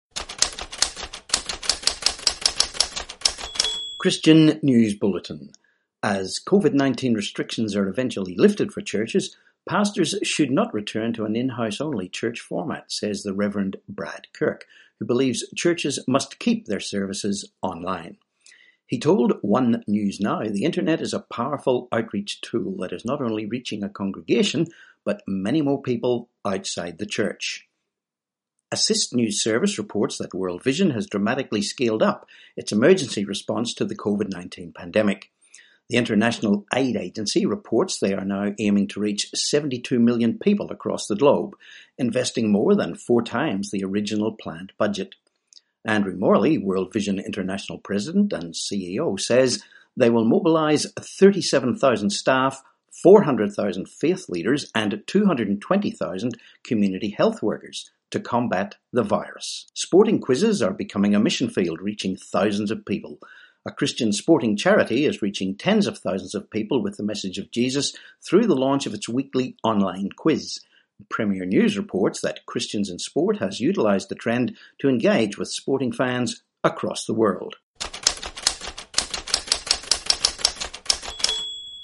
24May20 Christian News Bulletin